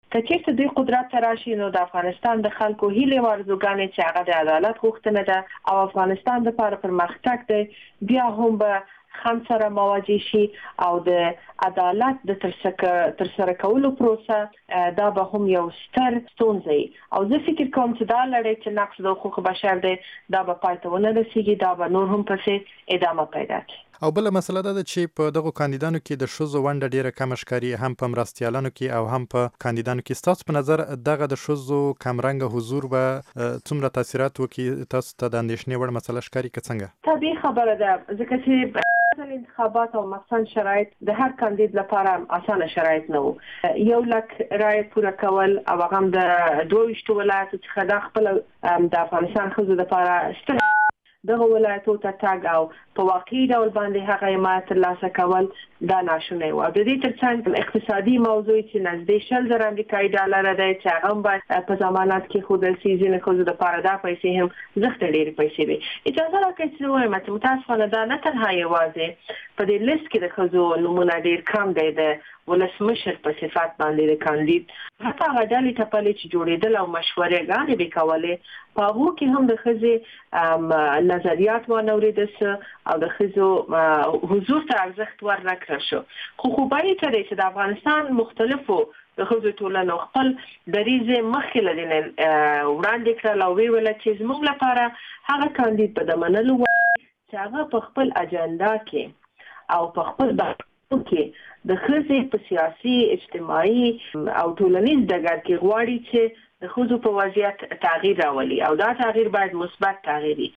له شکريې بارکزۍ سره مرکه